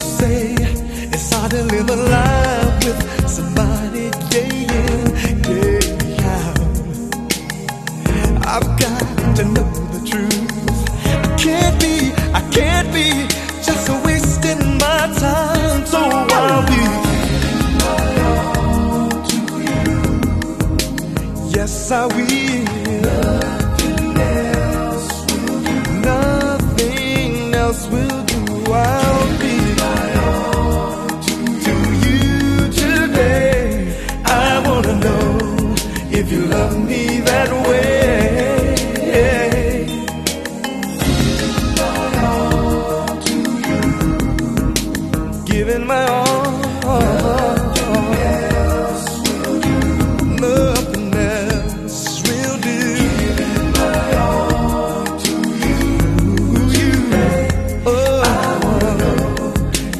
#90srnb